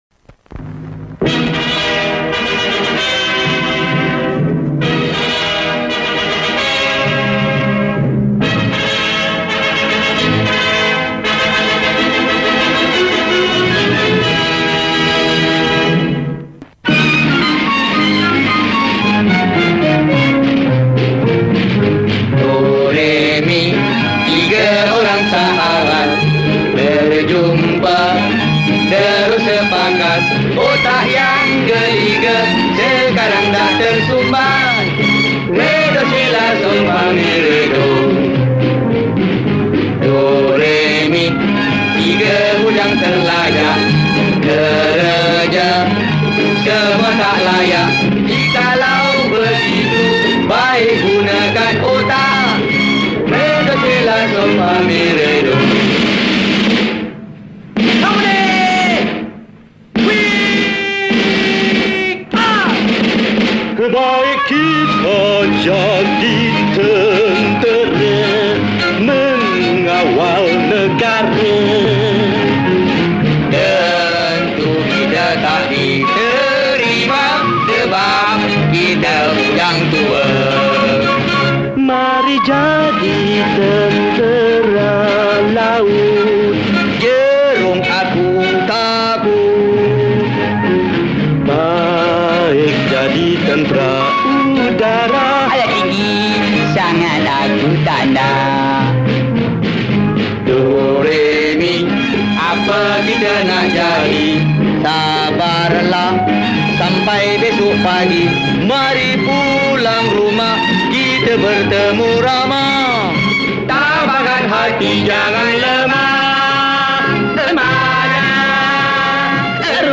Malay Song